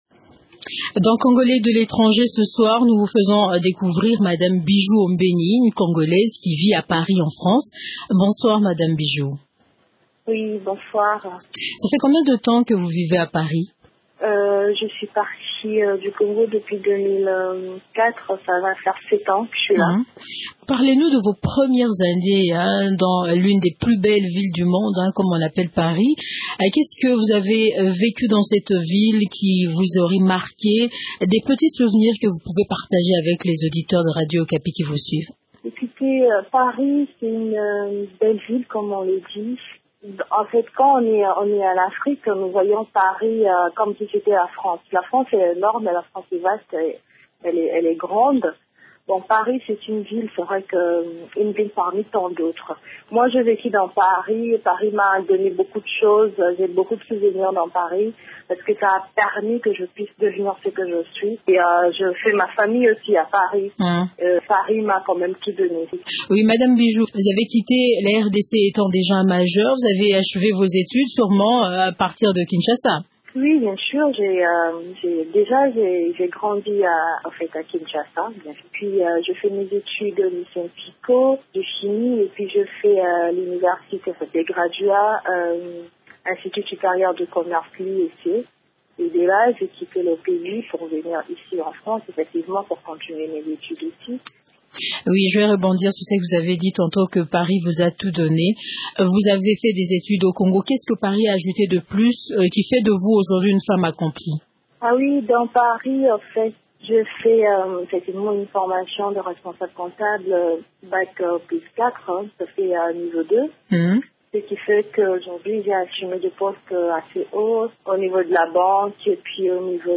Congolais de l'étranger, Émissions / diaspora